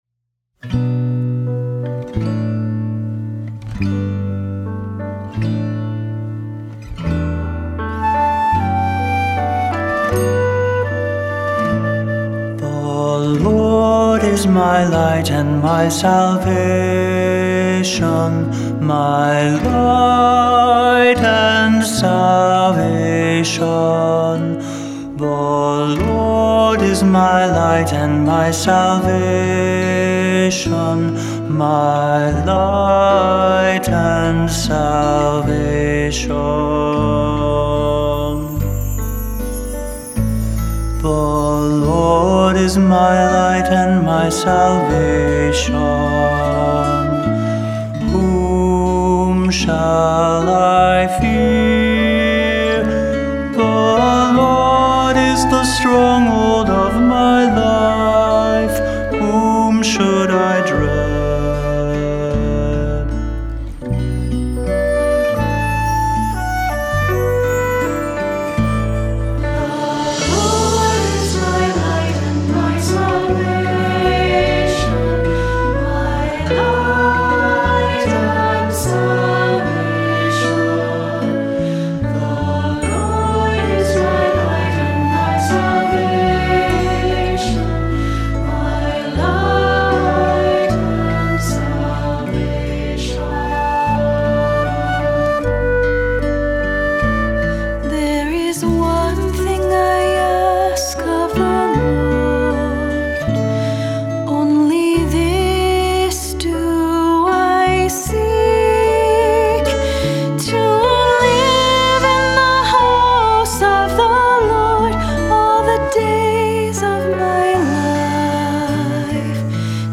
Voicing: "Unison with descant","Cantor","Assembly"